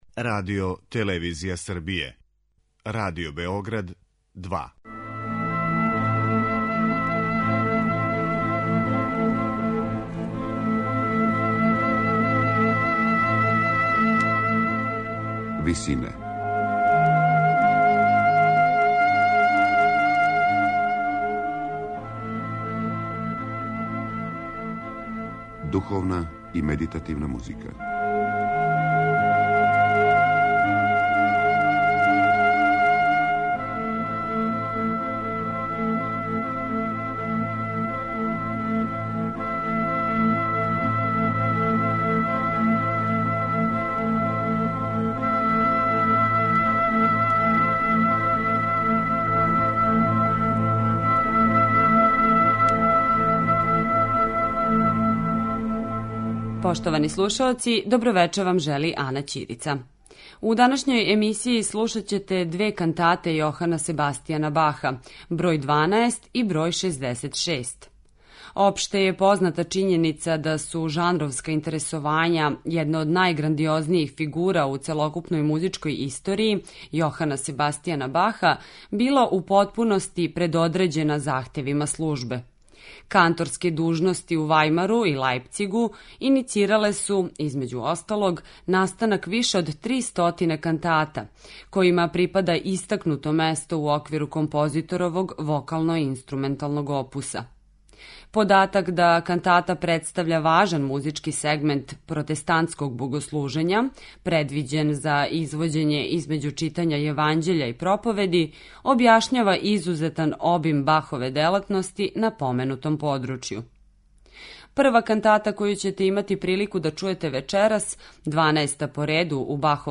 Бахове кантате
у ВИСИНАМА представљамо медитативне и духовне композиције аутора свих конфесија и епоха.
У другој овонедељној емисији Висине , посвећеној духовној и медитативној музици, слушаћете одабране кантате Јохана Себастијана Баха.